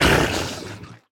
Minecraft Version Minecraft Version snapshot Latest Release | Latest Snapshot snapshot / assets / minecraft / sounds / mob / squid / death1.ogg Compare With Compare With Latest Release | Latest Snapshot